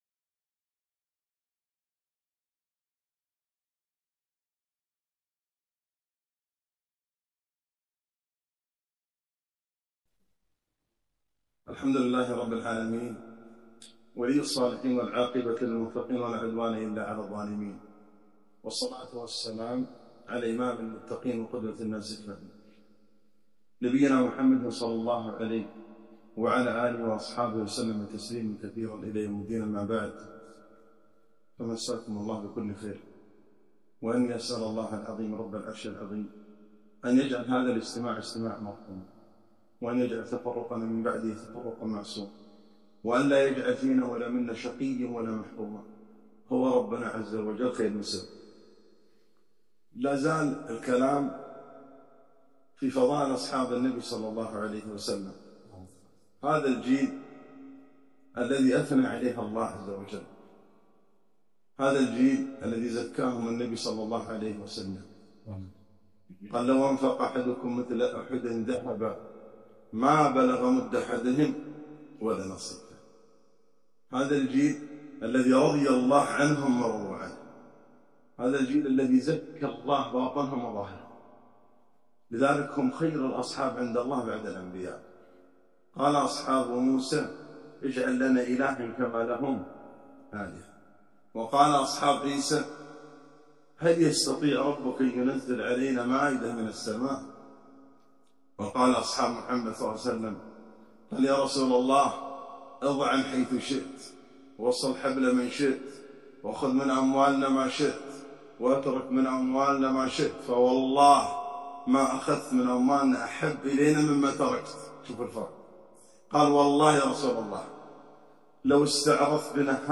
كلمة - خير الناس